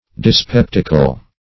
Dyspeptical \Dys*pep"tic*al\